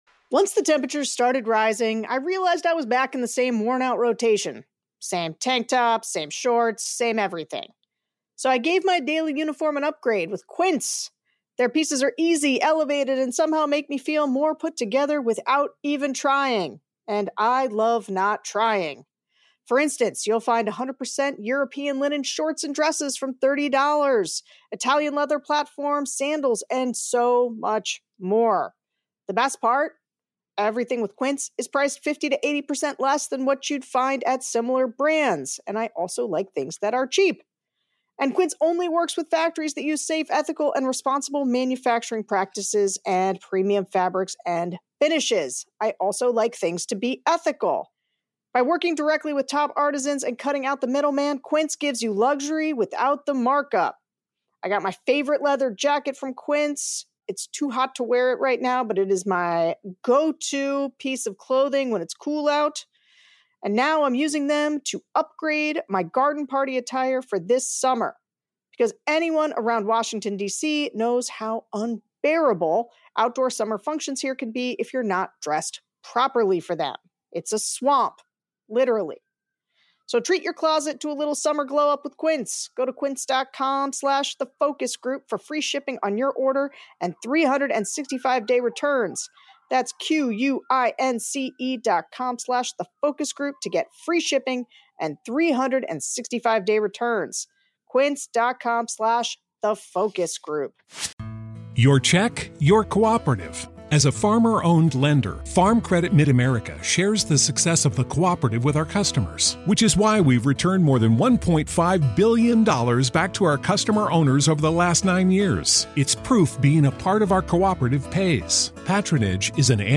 On this week's episode, we're sharing a director's cut of a recent focus group, featuring swing state suburban women.